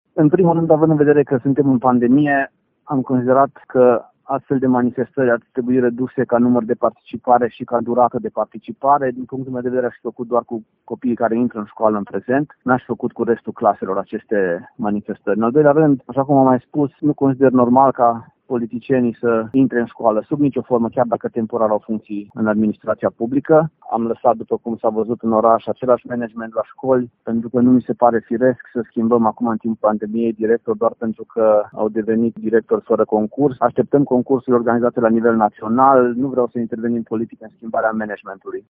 Edilul admite că școlile din oraș sunt departe de ceea ce ar trebui să fie, dar promite că în anii următori situația se va schimba radical: